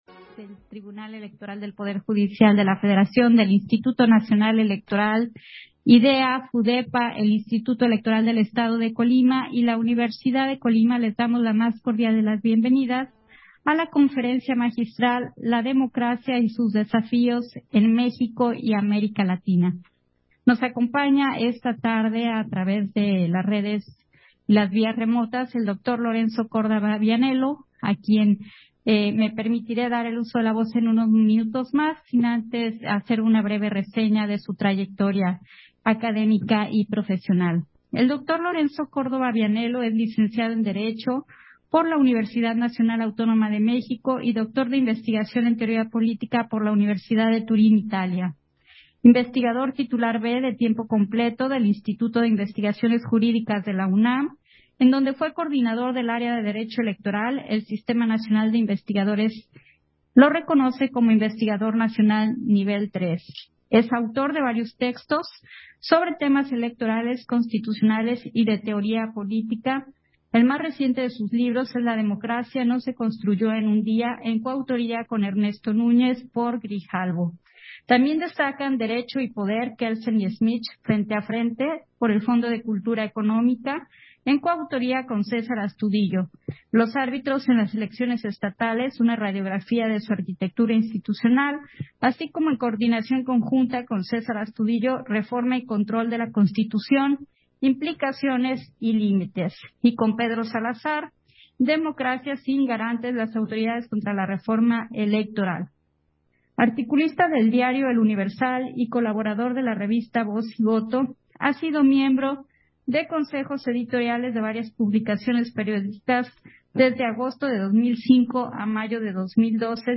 Conferencia magistral de Lorenzo Córdova, La democracia y sus desafíos en México y en América Latina